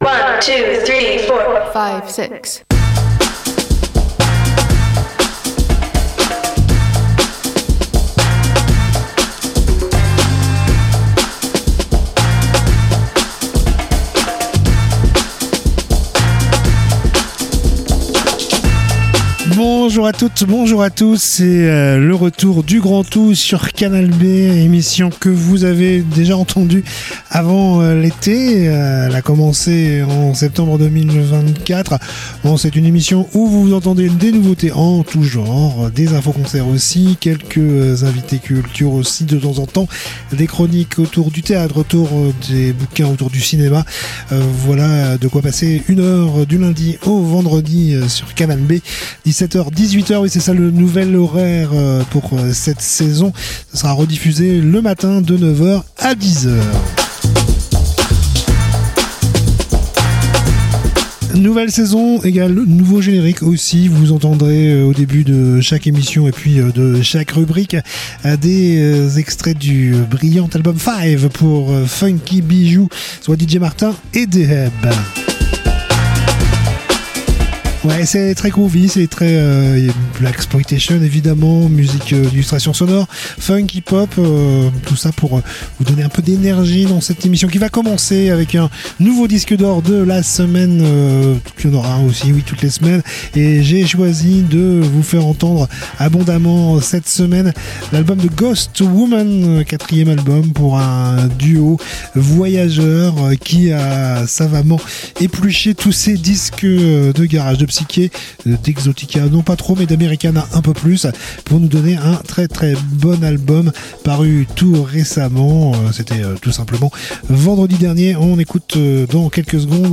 itv musique